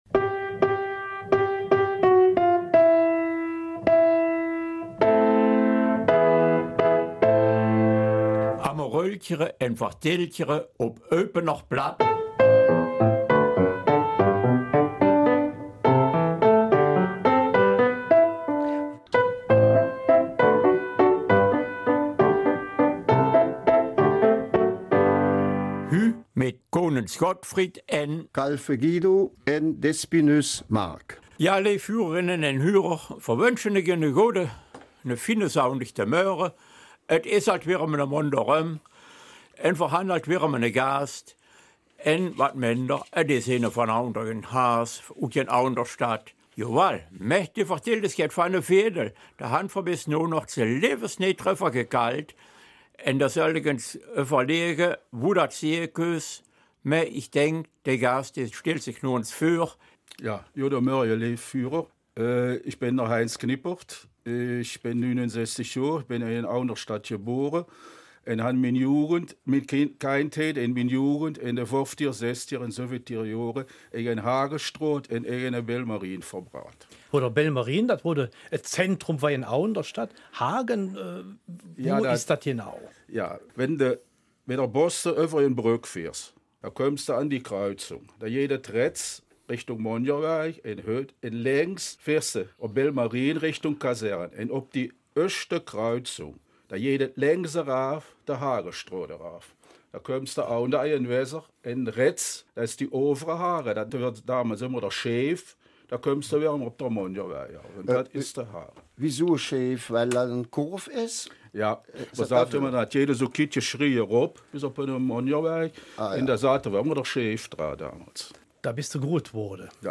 Eupener Mundart: Wir hatten es einfach gut, in unserem Viertel